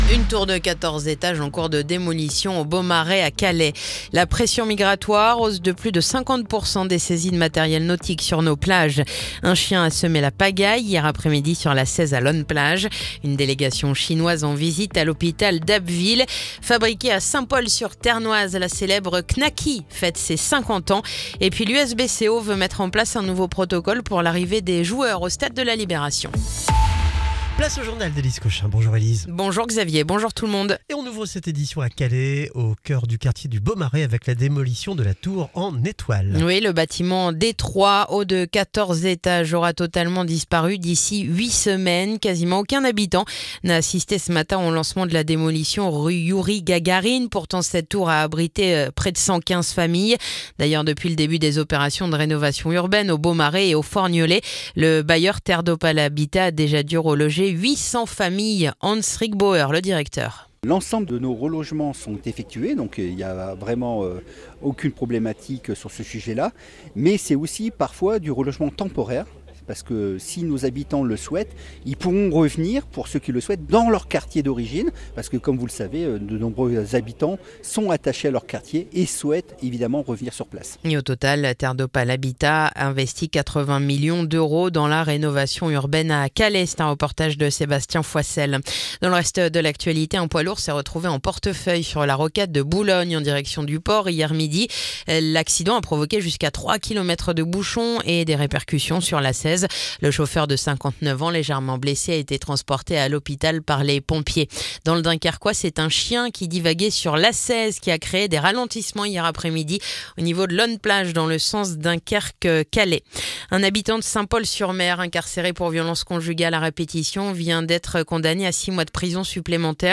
Le journal du jeudi 16 octobre